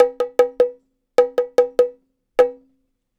Bongo Fill 06.wav